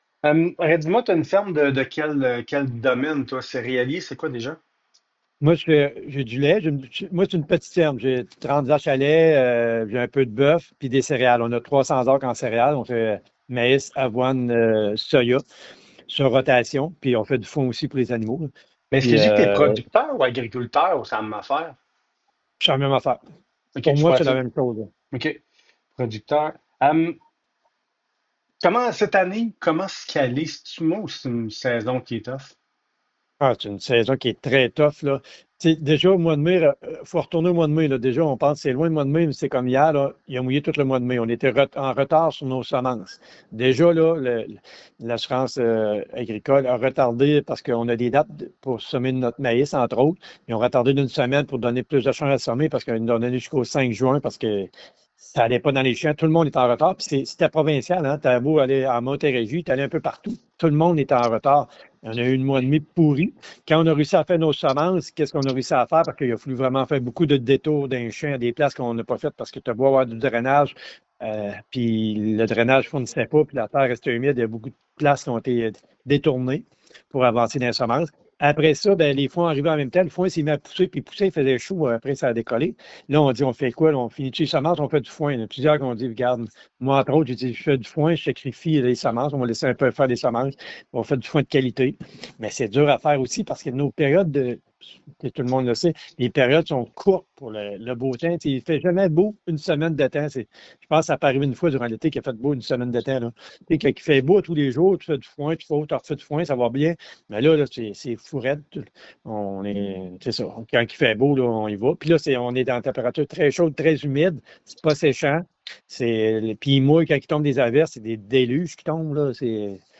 Lors de la séance du conseil des maires, mercredi soir, le préfet de la MRC, Mario Lyonnais, a expliqué qu’il s’agissait de présenter une image qui colle davantage à la réalité d’aujourd’hui.